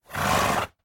mob / horse / idle1.ogg